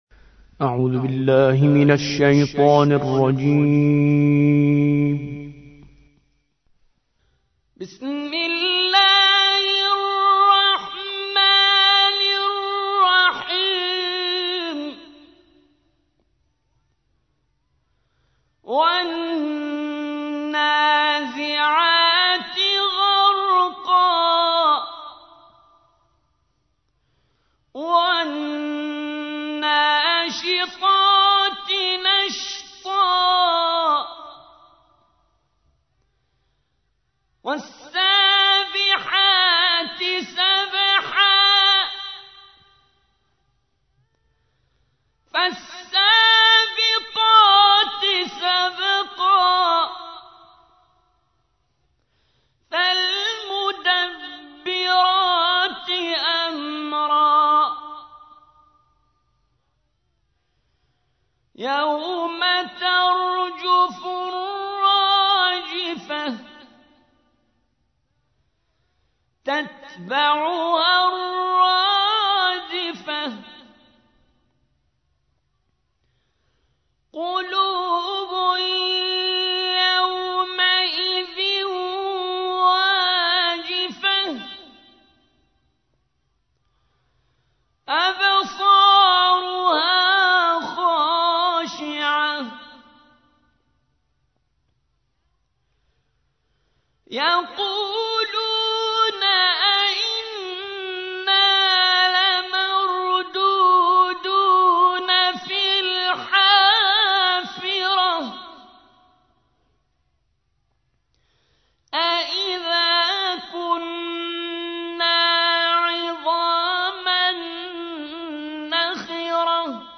79. سورة النازعات / القارئ